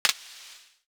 Sizzle Click 1.wav